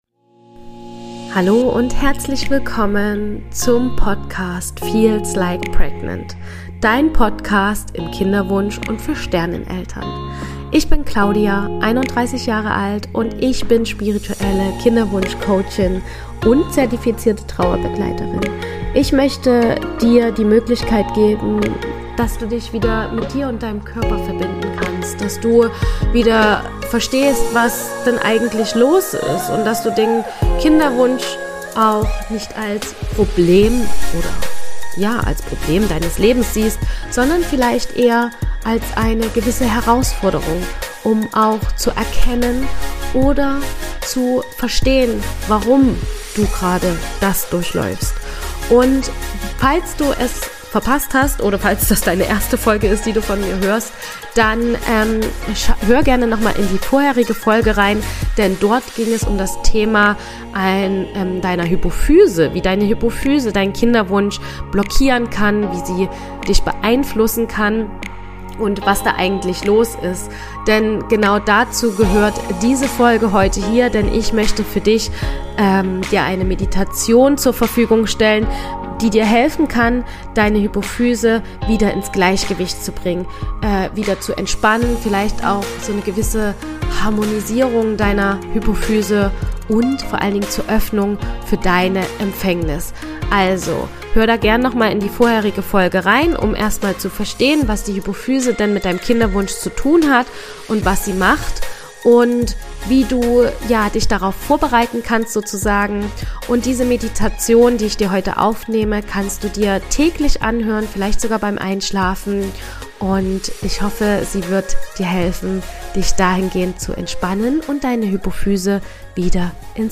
Meditation zur Aktivierung deiner Hypophyse